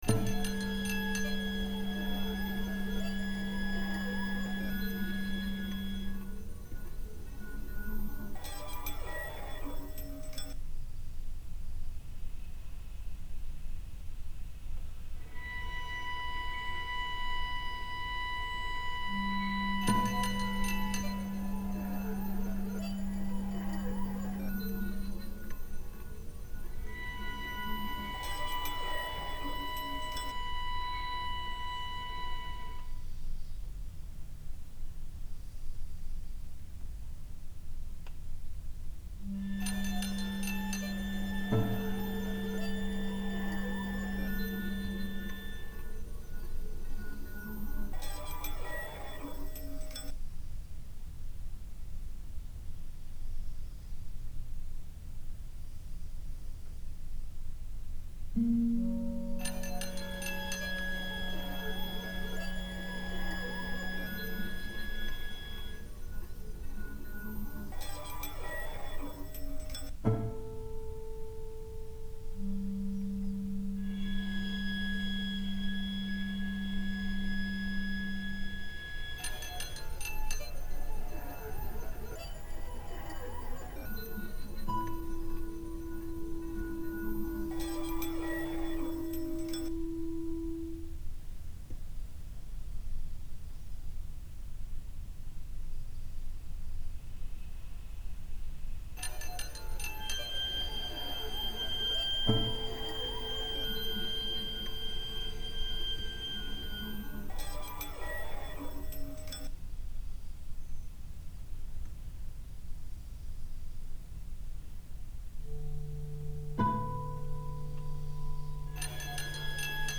International Publisher and label for New experimental Music
electronic music